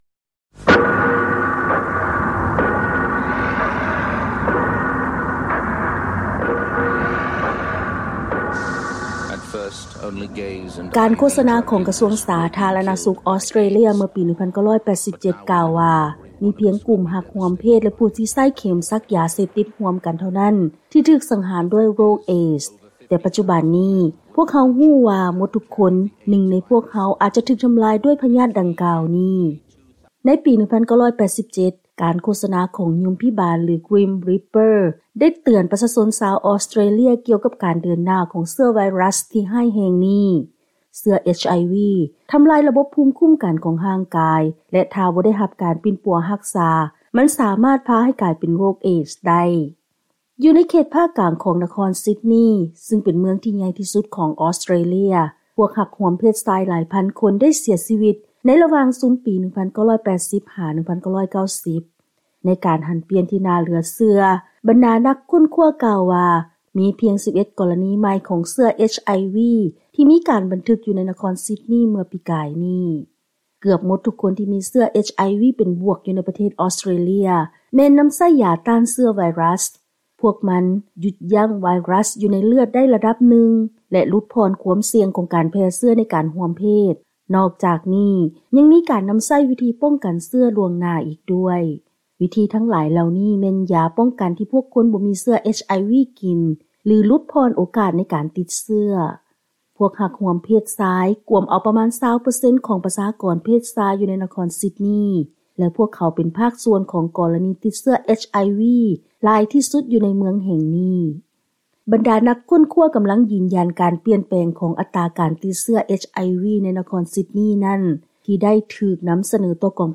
ເຊີນຮັບຟັງລາຍງານກ່ຽວກັບ ການປະກາດຄວາມກ້າວໜ້າກ່ຽວກັບເຊື້ອພະຍາດ HIV ຂອງນັກຄົ້ນຄວ້າຊາວອອສເຕຣເລຍ